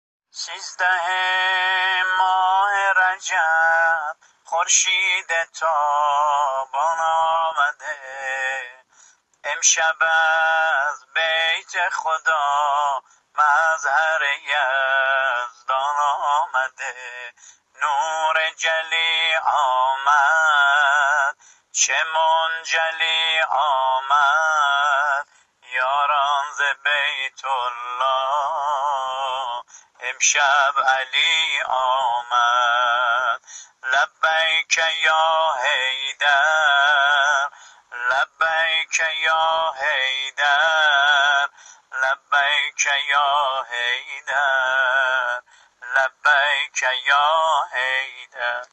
(سرود٣۴)